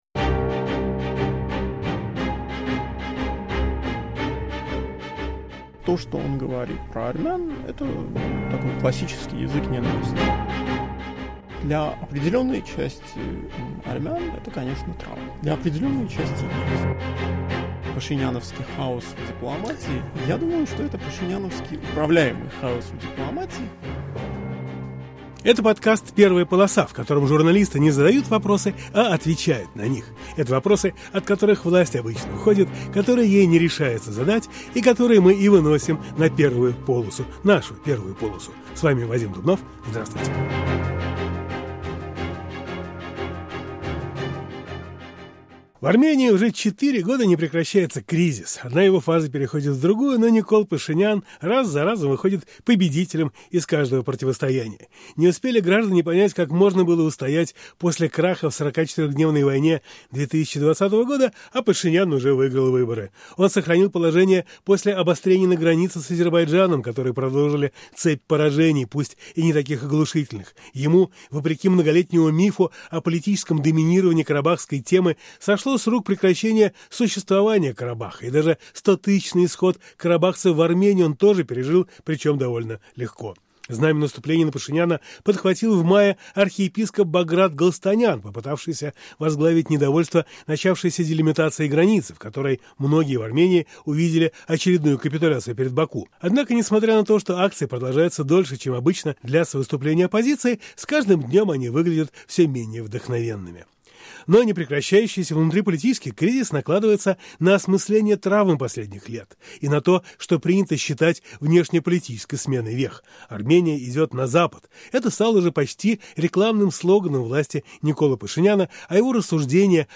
Интервью с журналистами Радио Свобода о ситуации в странах вещания медиа-корпорации